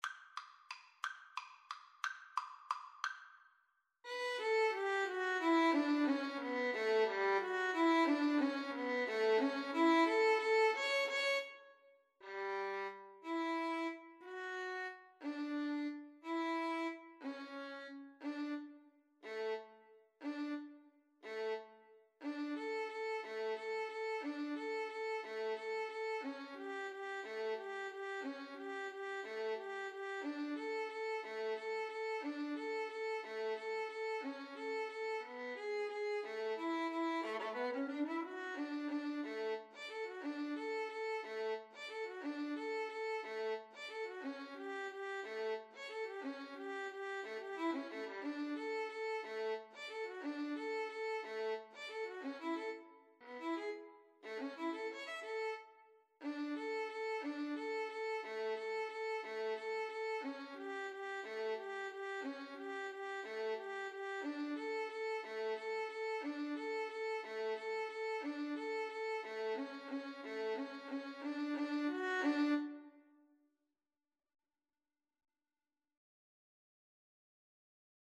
Violin 1Violin 2
. = 60 Tempo di Valse ( . = c. 60)
3/4 (View more 3/4 Music)
Violin Duet  (View more Intermediate Violin Duet Music)
Classical (View more Classical Violin Duet Music)